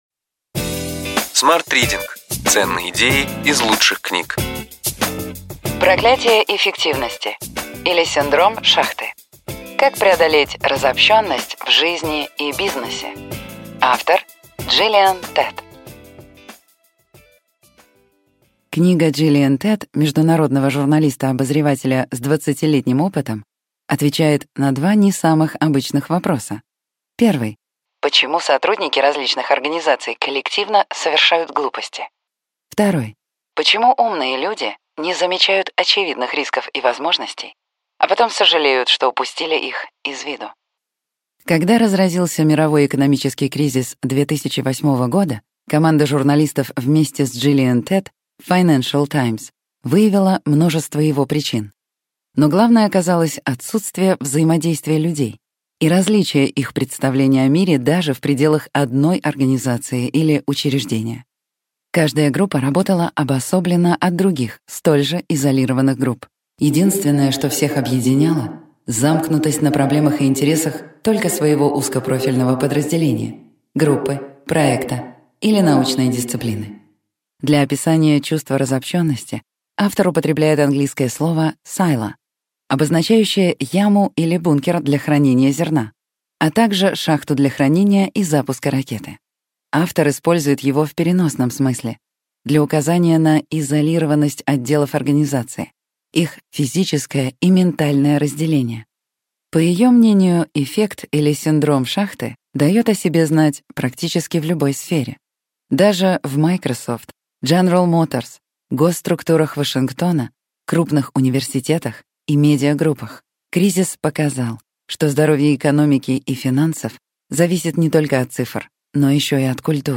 Аудиокнига Ключевые идеи книги: Проклятие эффективности, или Синдром «шахты». Джиллиан Тетт | Библиотека аудиокниг